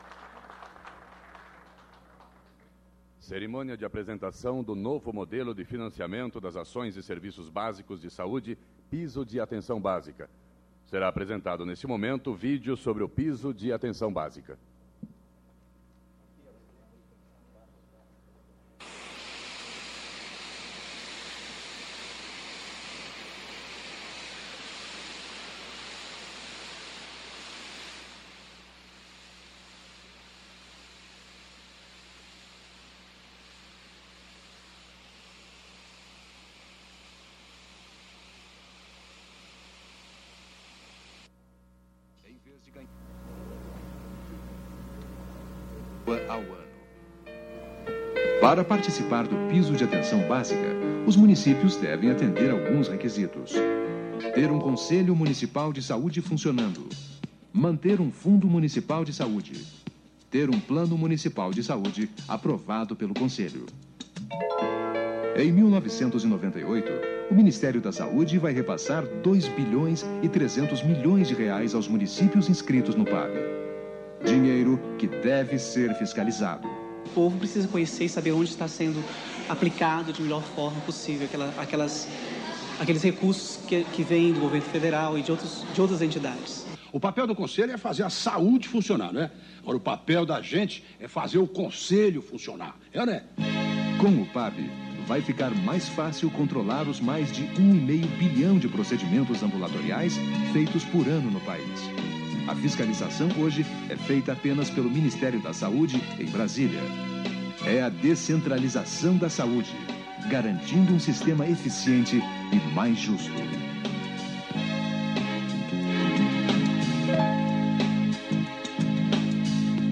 Clipe de Áudio: Carlos César de Albuquerque (Ministro da Saúde) explica o PAB.